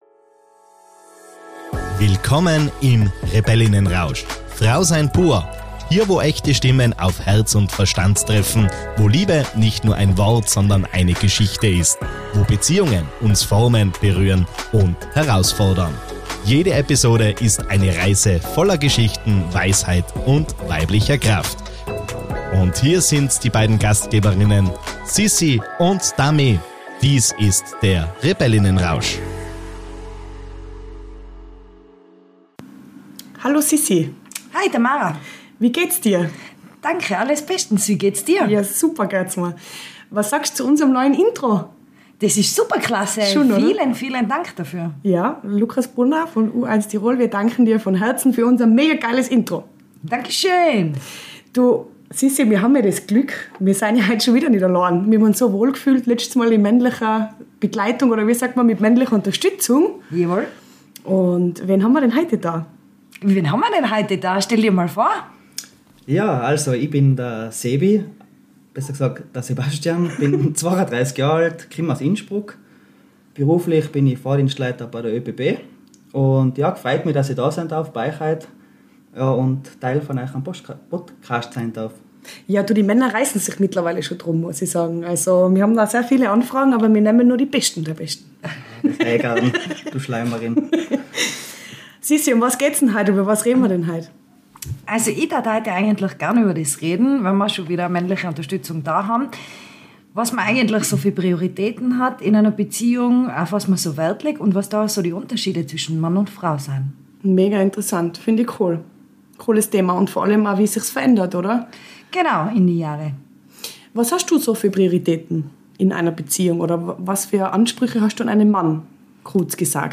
In dieser Folge geht es, wieder mit männlicher Unterstützung, um Prioritäten in einer Beziehung und wie sich diese in den Jahren verändern.